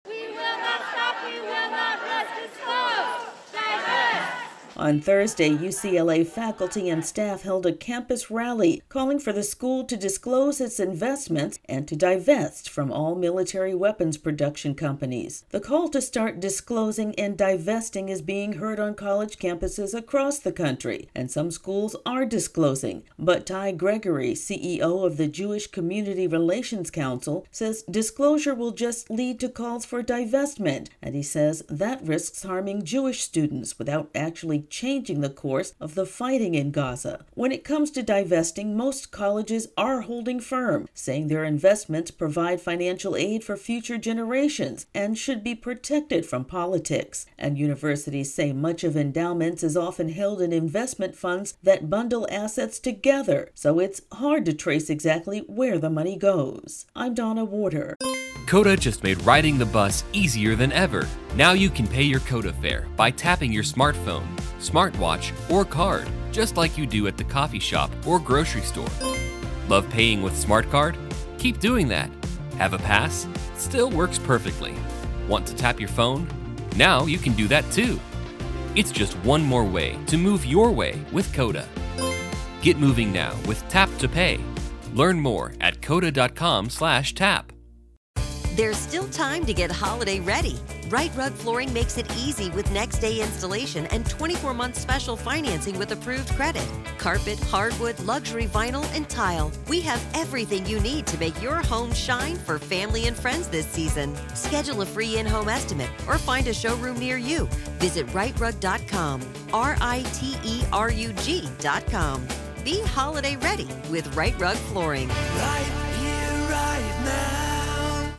((Opens with protest sound))